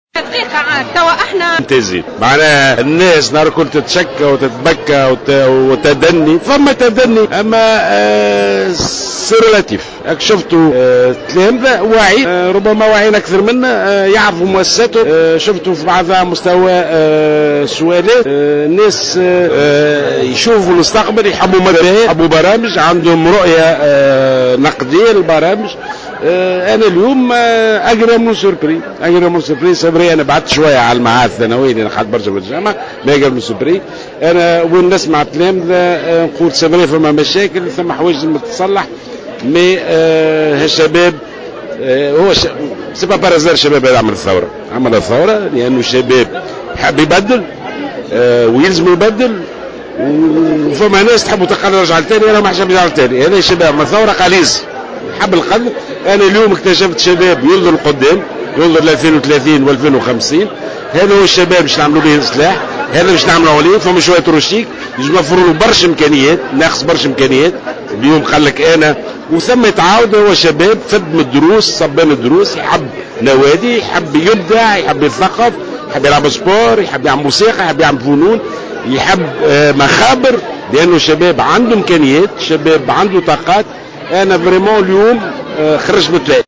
عبر وزير التربية د ناجي جلول على هامش حضوره احدى حصص الحوار مع التلاميذ اليوم الجمعة 15 ماي 2015 عن تفاجئه من المستوى الممتاز لوعي التلميذ التونسي مؤكدا أنه خرج بانطباع جيّد عن مستوى الرؤى الاصلاحية لديهم بخصوص المنظومة التربوية.